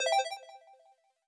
Modern UI SFX / AlertsAndNotifications
Success7b.wav